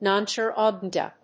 nonchargeable.mp3